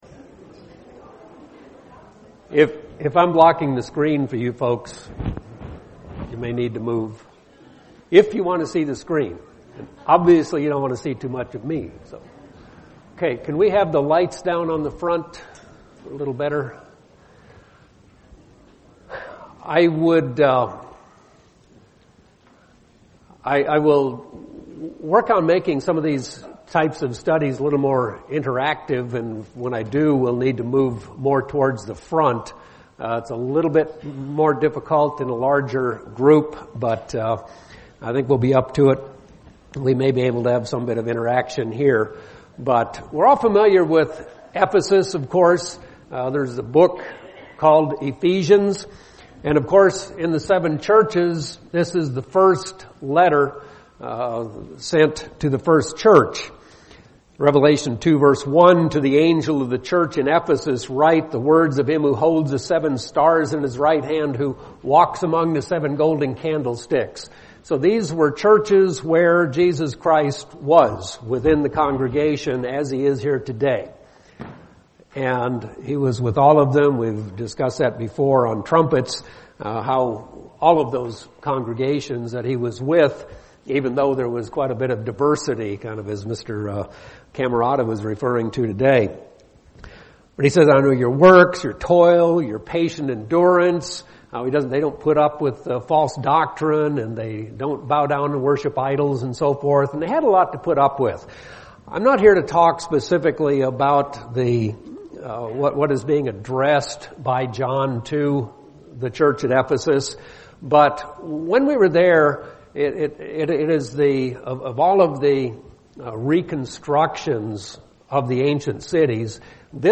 A Bible study on events that occurred in the City of Ephesus. Acts 18-19, and things about “John’s Baptism."